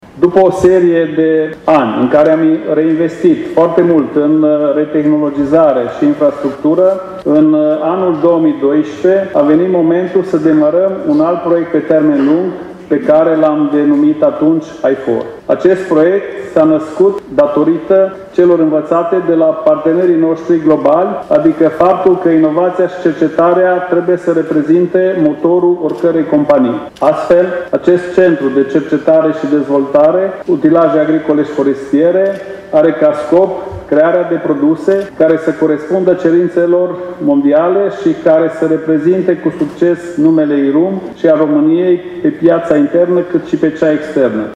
Centrul a fost inaugurat astăzi, în prezența câtorva sute de persoane, angajați, parteneri de afaceri și reprezentanți ai autorităților locale.